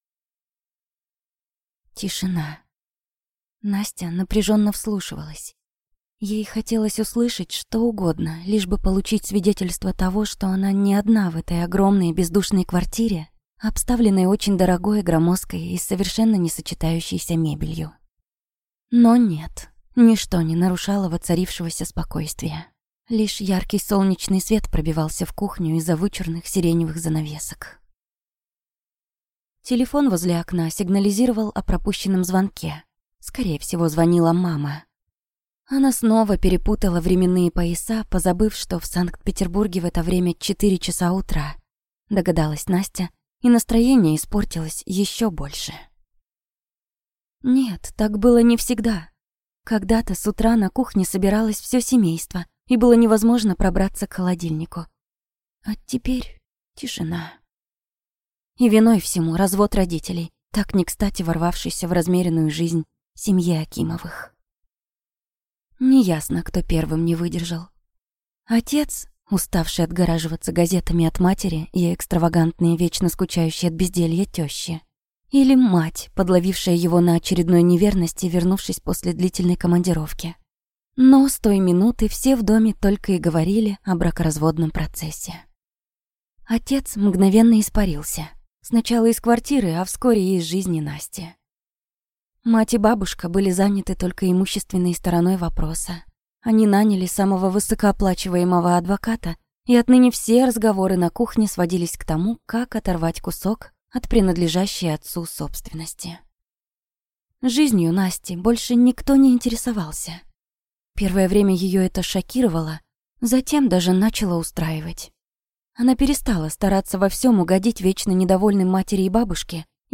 Аудиокнига Я выбираю тебя | Библиотека аудиокниг